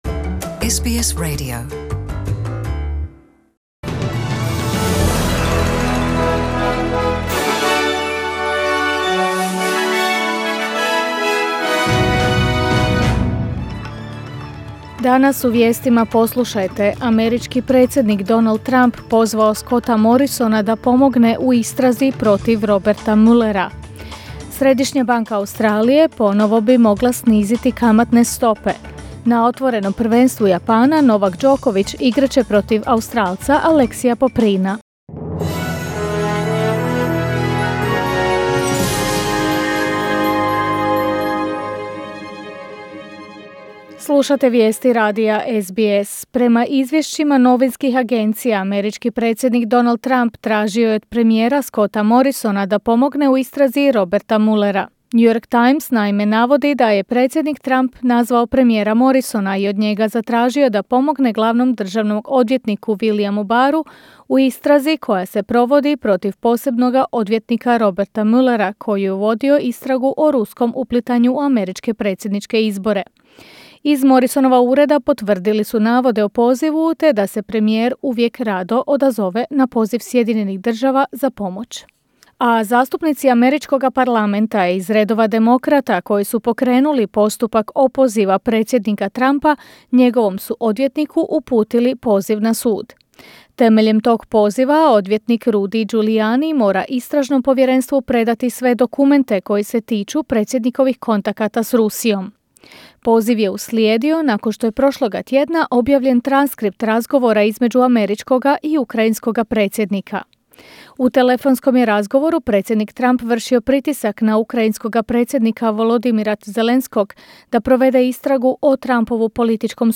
Vijesti radija SBS